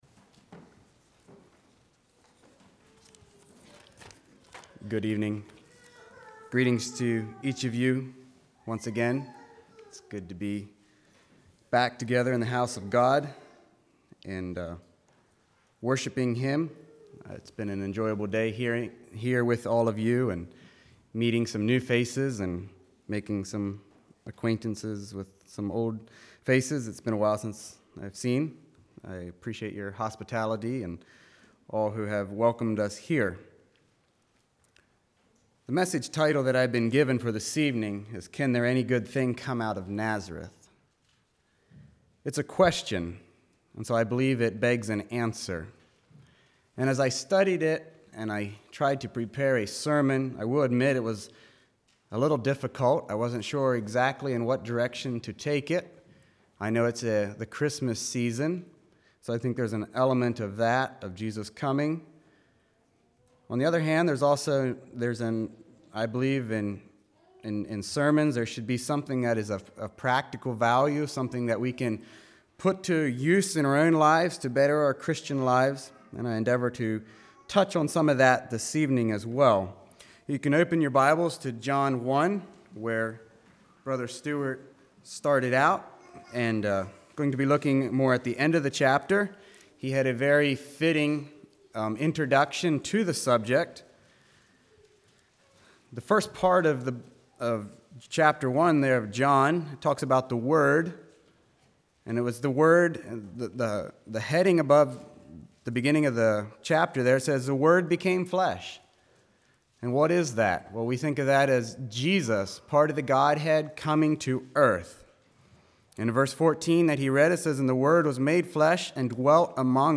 2020 Sermon ID